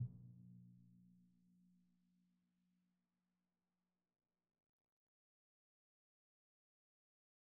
Timpani2_Hit_v1_rr2_Sum.wav